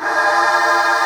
CHOIR 2.wav